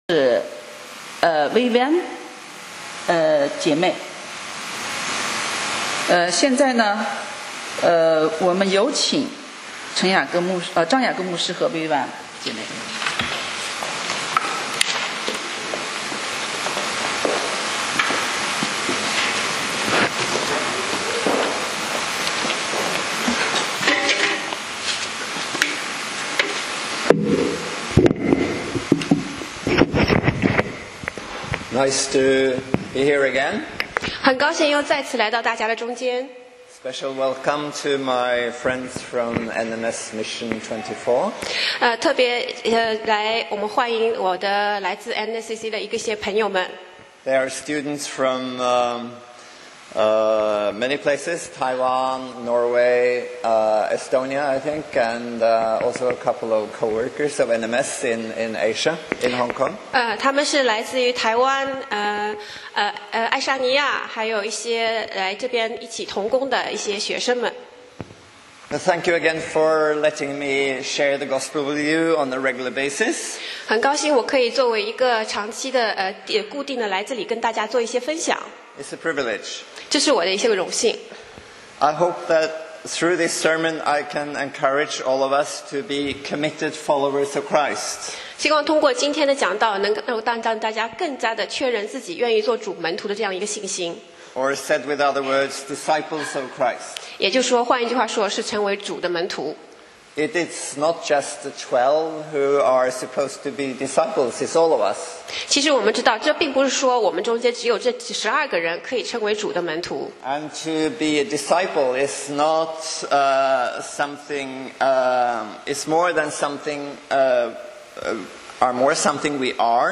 講道Sermon 題目：在光里行走 Topic： Walking in the light 經 文：约翰福音3:16-21节 Verses： John 3:16-21. 16.神爱世人，甚至将祂的独生子赐给他们，叫一切信祂的，不至灭亡，反得永生。